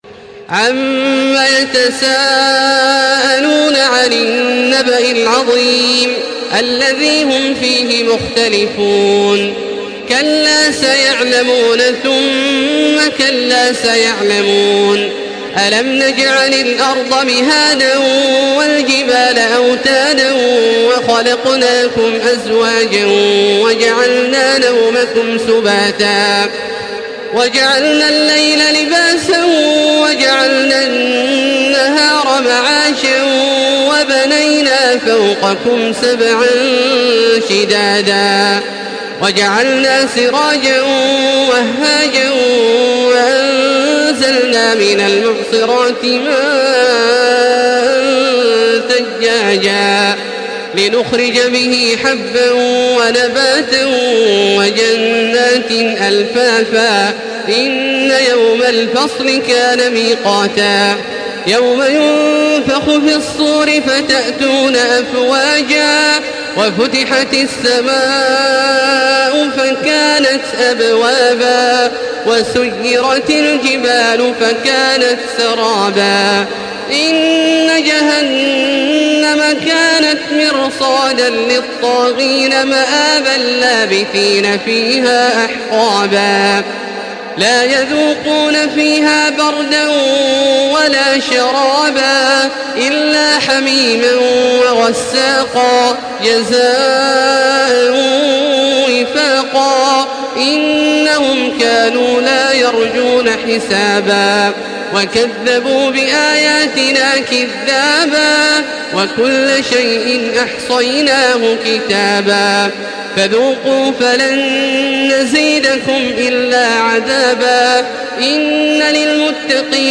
تراويح الحرم المكي 1435
مرتل